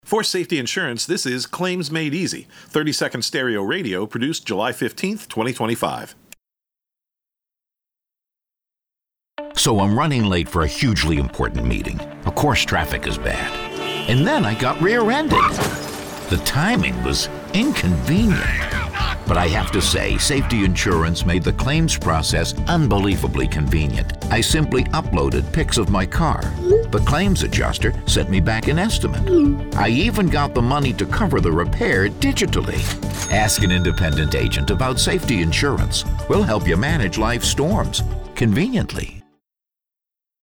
Versatile, Powerful, Directable
Claims Made Easy 30 RADIO July_15_2025.mp3
Middle Aged